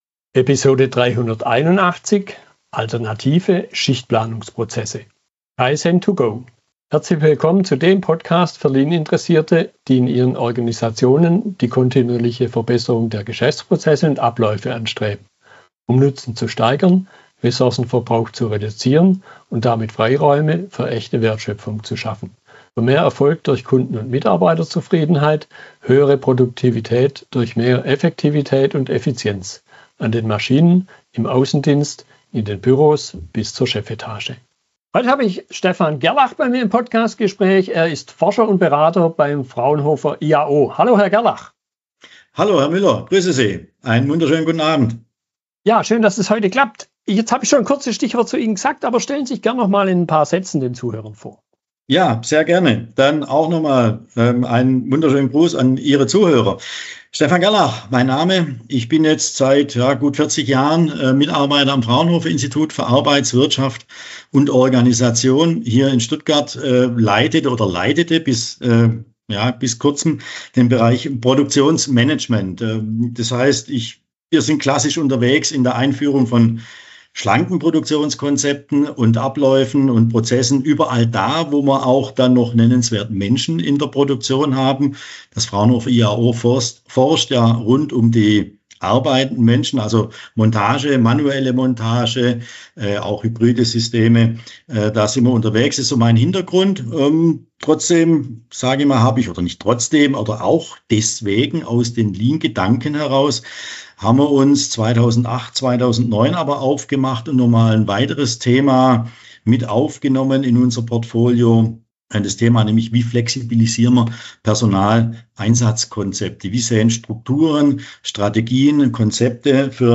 Fragestellungen aus der Unterhaltung